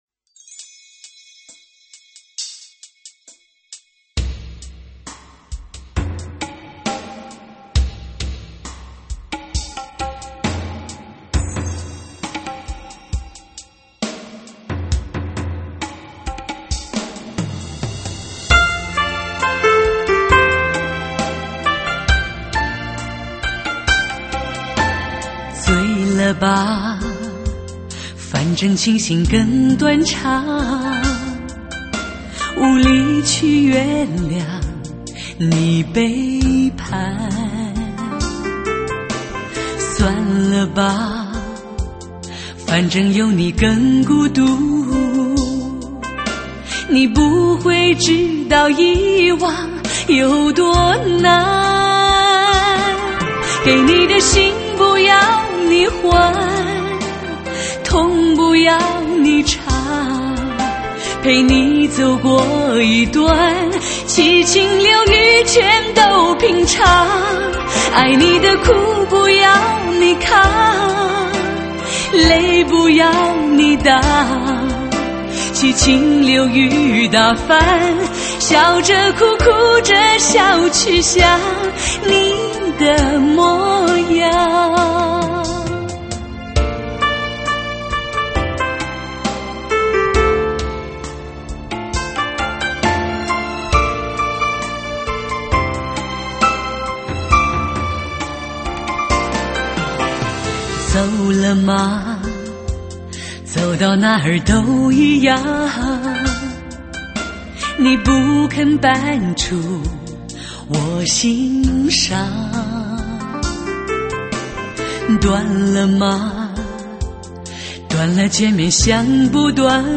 清新音色，磁性迷人歌喉，一声声，一首
真正实现车内空间6.1Simulation 360度环绕HI-FI音效！